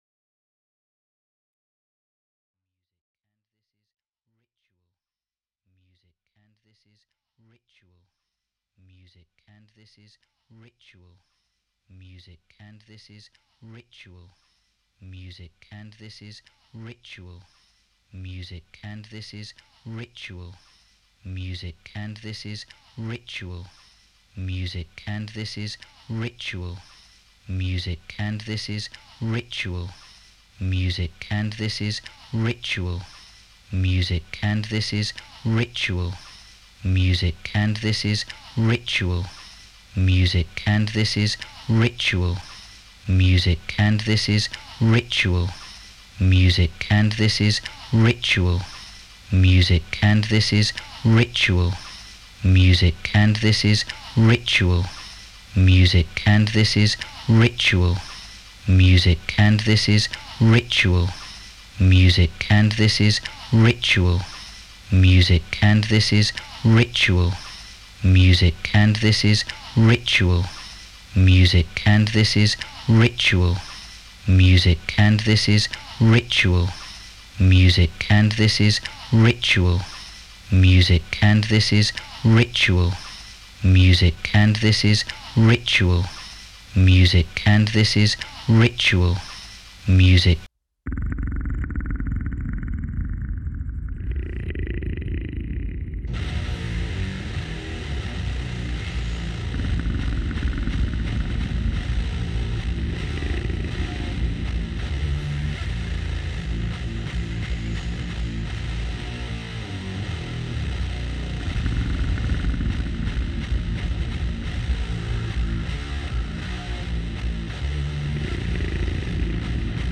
The playlists for the show are created using a variety of chance operations; primarily, I rely on the prescriptions of a computer program (written in Python) that randomly outputs links to music files/excerpts from an eclectic and continuously growing archive that I maintain.